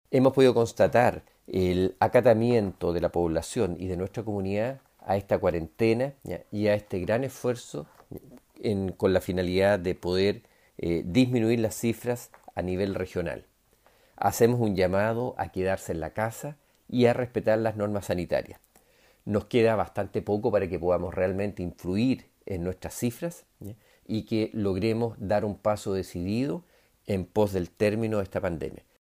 El seremi Alejandro Caroca resaltó este comportamiento de la comunidad de Los Lagos, porque el objetivo es ayudar a disminuir los números de contagios, manifestó el personero del Minsal.